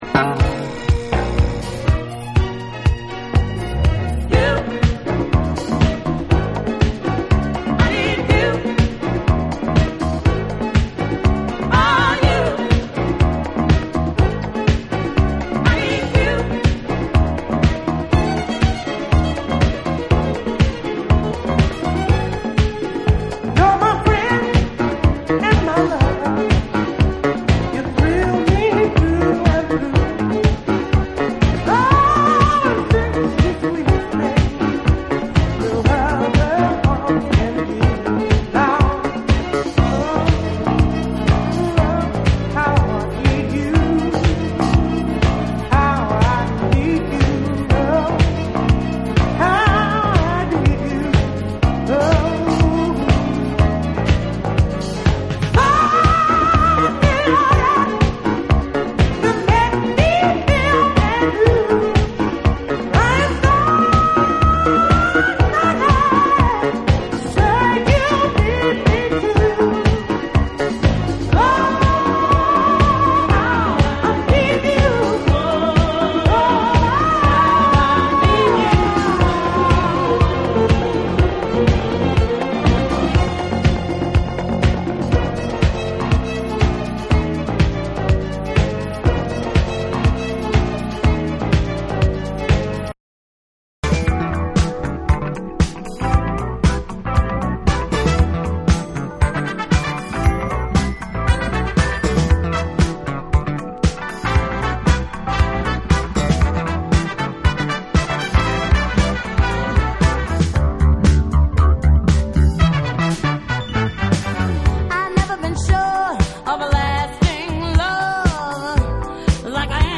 ディスコ・クラシックを集めたコンピレーション作品